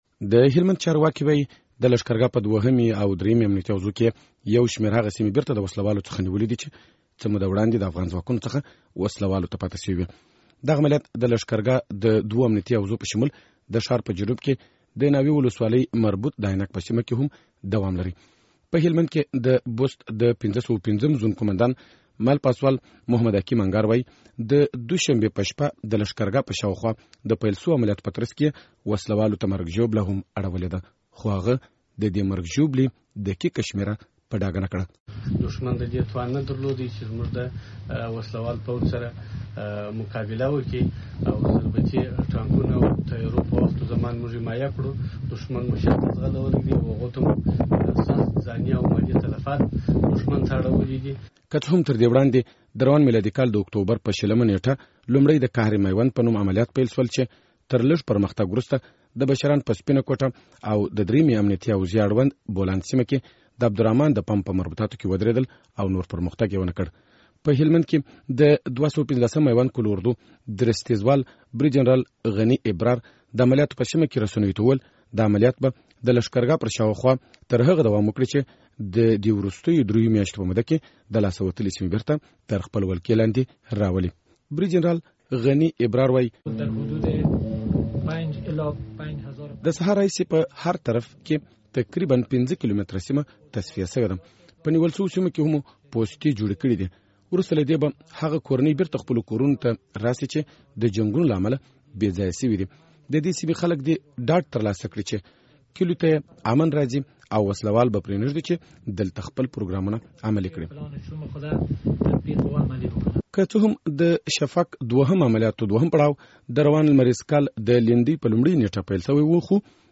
هلمند راپور